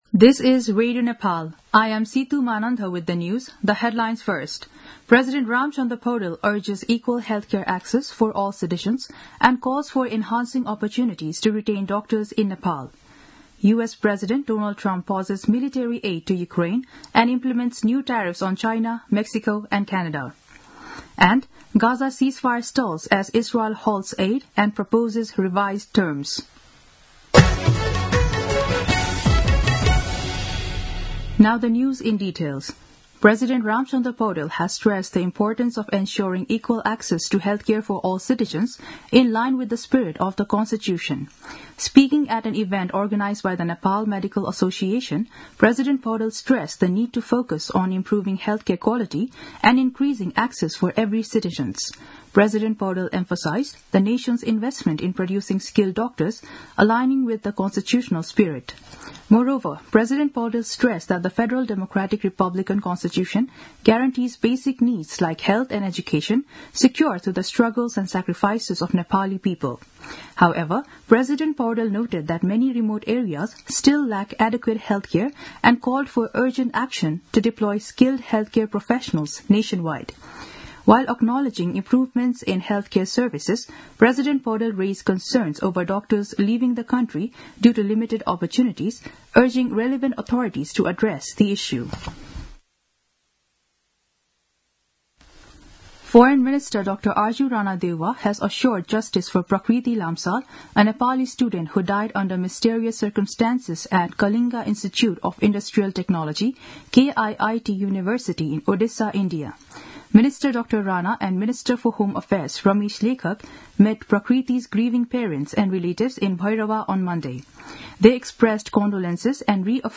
दिउँसो २ बजेको अङ्ग्रेजी समाचार : २१ फागुन , २०८१